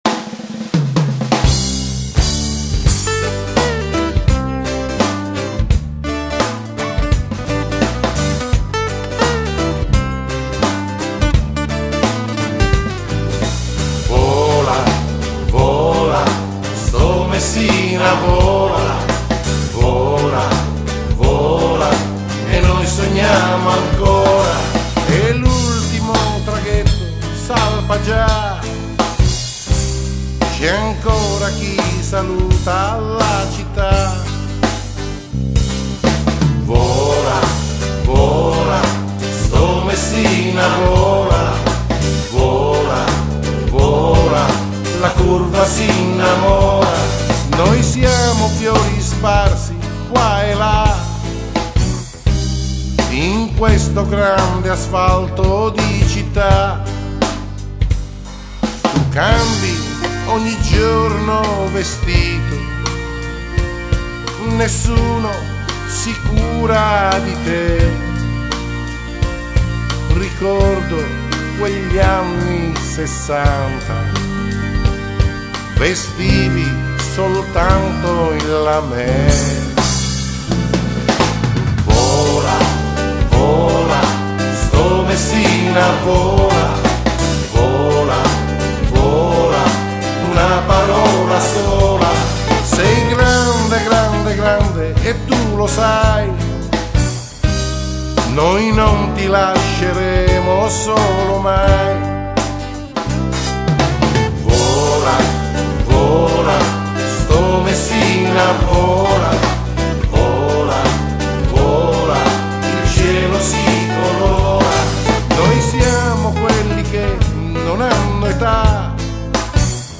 Inno: